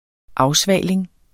Udtale [ ˈɑwˌsvæˀleŋ ]